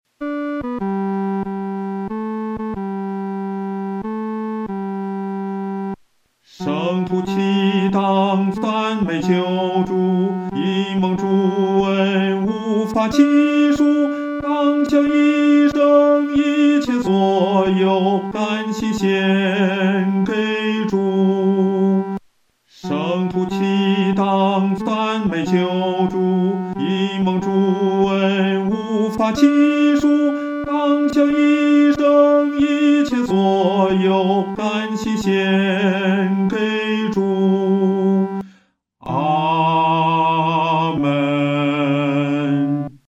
男高